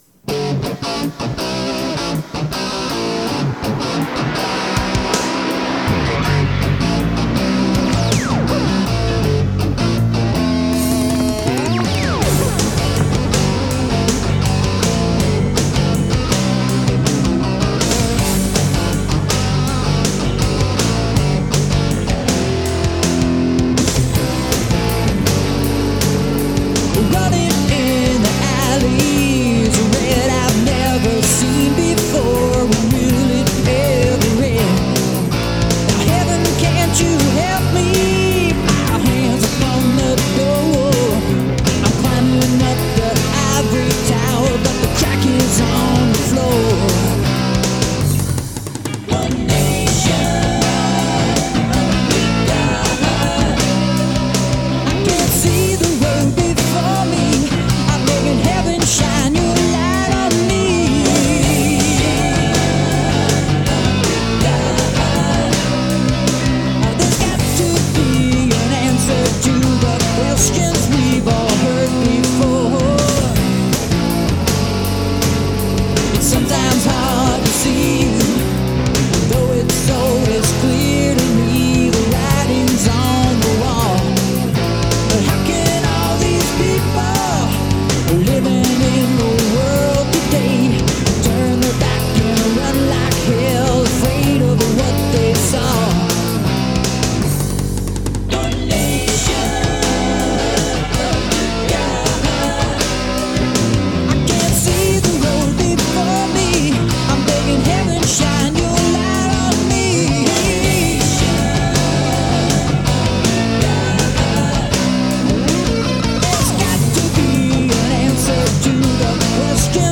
Recorded at Rabid Ears Studio - Lancaster, Pa.